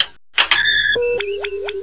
SFX: computer sounds from main bridge